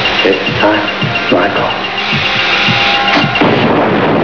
Loomis talking to Michael, at the end of the movie.